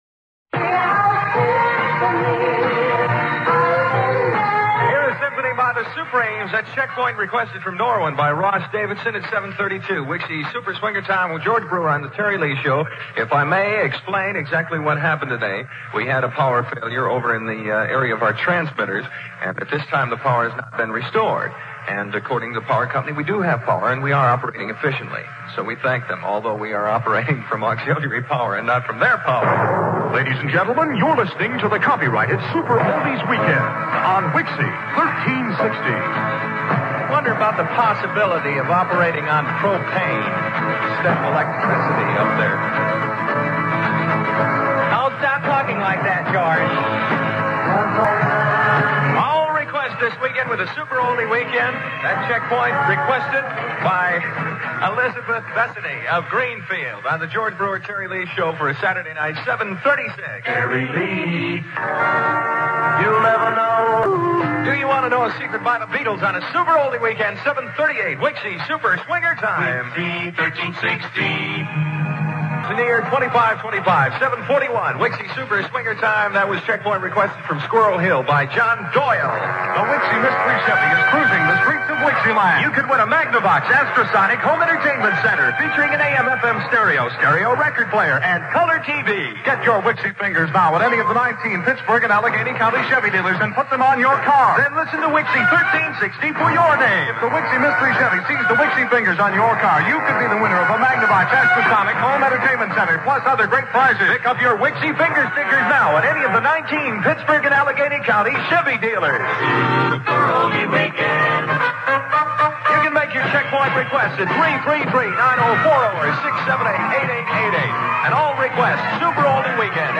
LISTEN> | Aircheck recorded 7/5/1969